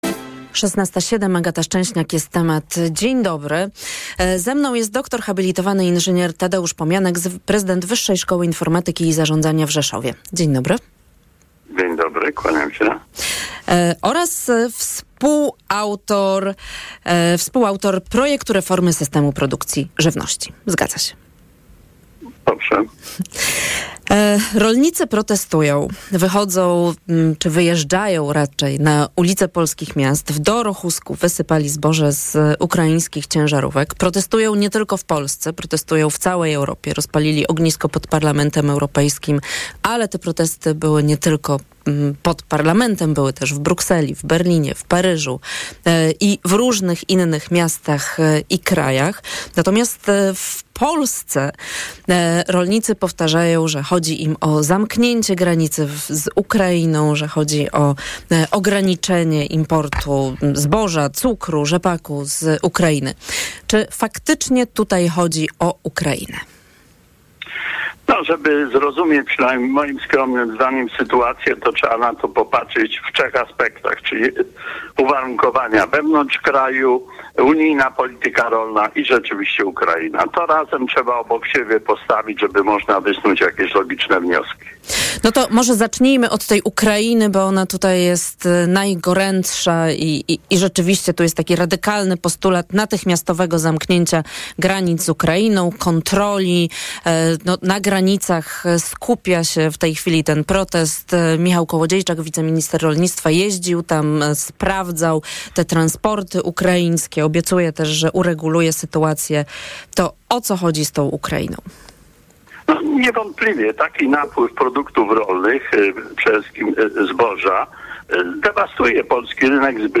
Nagranie wywiadu znajduje się TUTAJ.